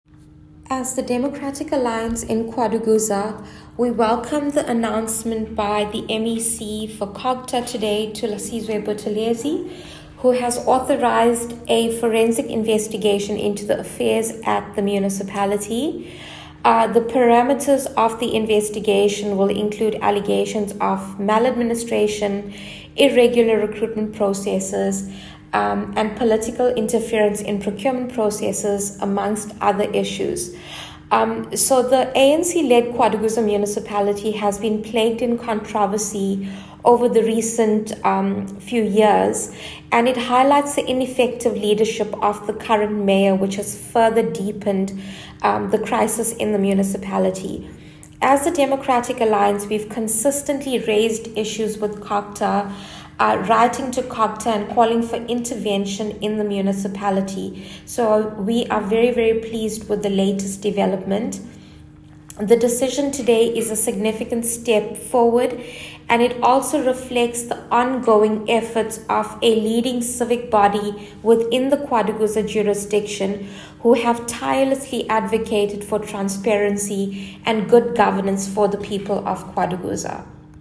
Note to editors: Find Soundbite in English